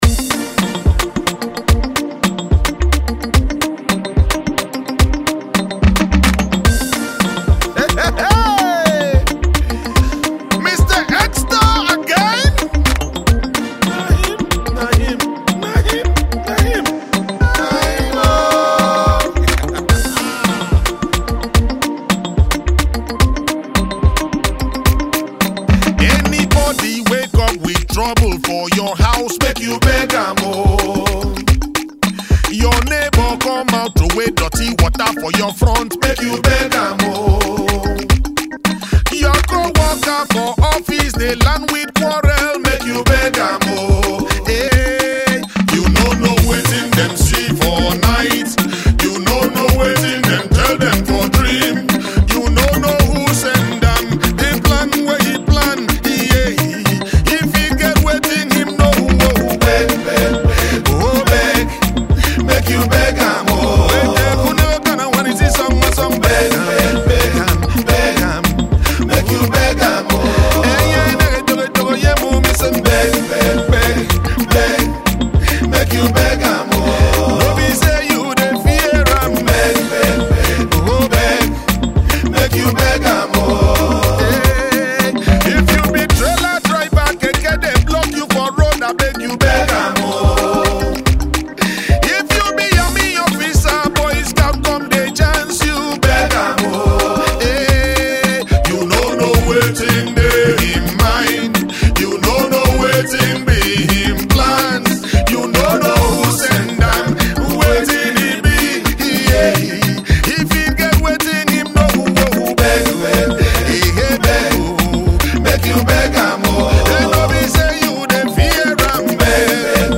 Afrobeat
feel-good sing and dance along tune